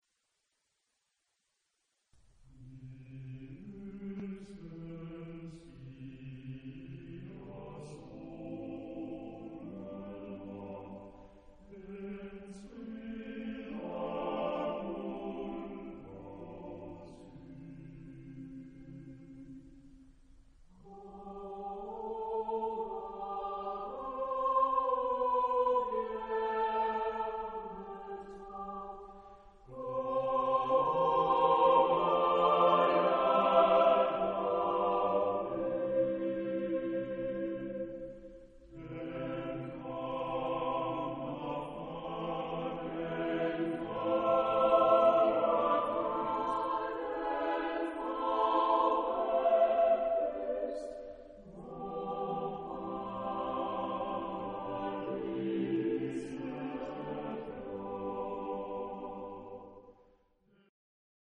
SSATTBB (7 voices mixed) ; Choral score.
Cantata chorus. hymn (secular).
Mood of the piece: Romantic ; expressive Type of Choir: SSATTBB (7 mixed voices )
Tonality: C major